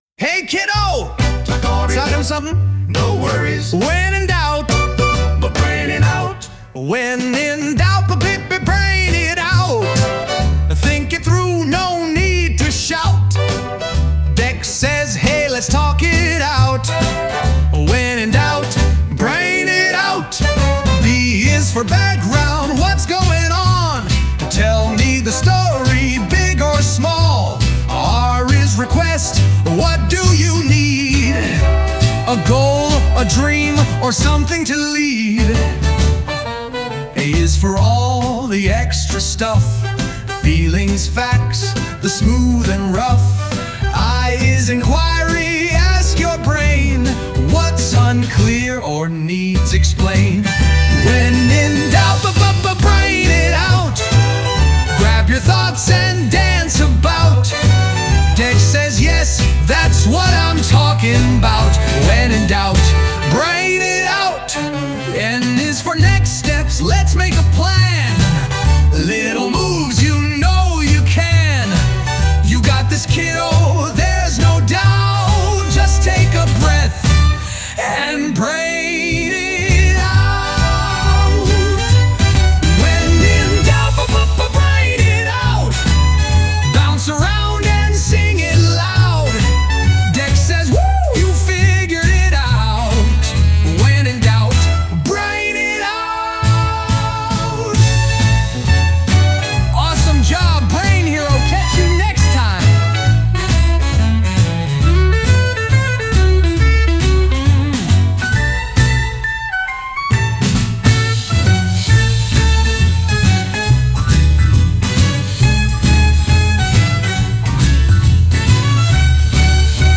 • Key: C Major
• Tempo: 100 BPM
• Vibe: Energetic, playful cartoon anthem
• Character Lead: Dex (Robot-style voice)
• Style: Pop-funk kids’ theme song with handclaps and syncopation
• Bass: Root-driven C-G-F-C groove, slap-friendly during chorus, slides from C to G
• Drums: 4/4 bounce, clap accents on 2 & 4, hi-hats closed for verses
• Guitar: Clean, funky upstrokes or muted rhythm comping
• Vocals: Animated, friendly, with Dex-style robotic inflections